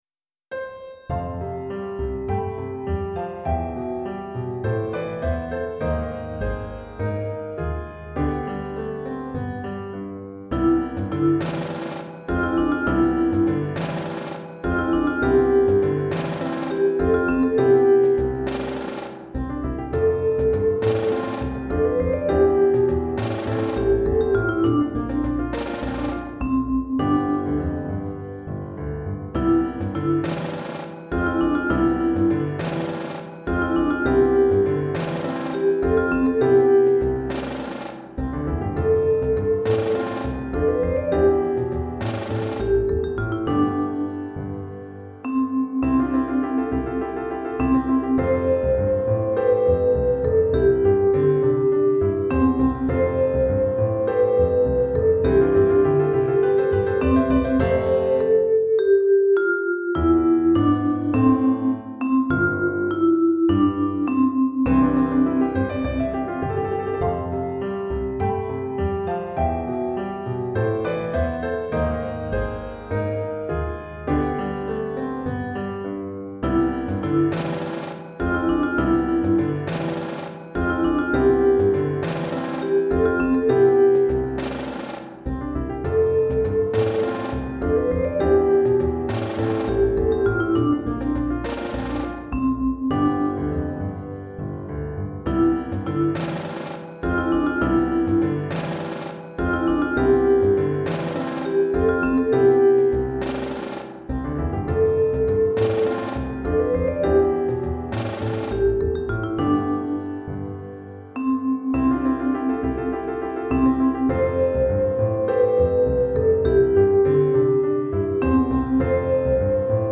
Chorale d'Enfants (7 à 11 ans) et Piano